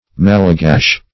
Malagash \Mal`a*gash"\, n.